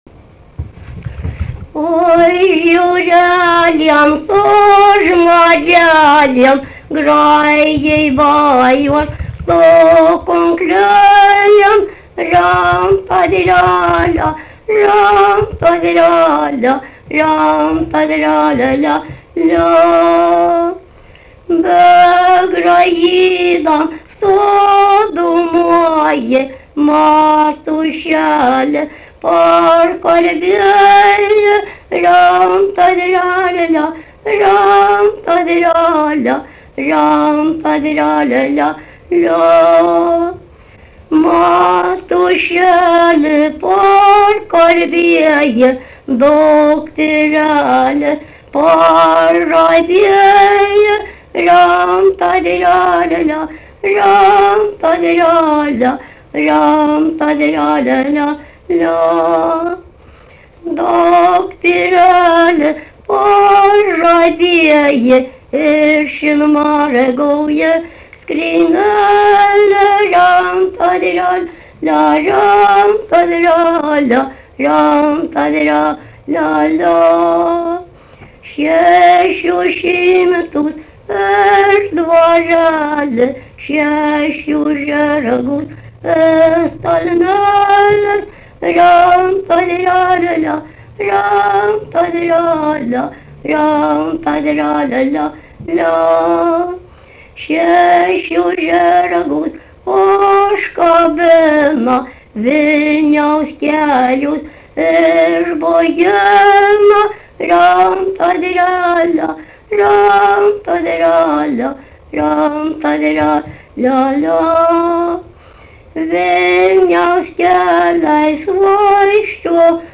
Dalykas, tema daina
Erdvinė aprėptis Daukšiai (Skuodas)
Atlikimo pubūdis vokalinis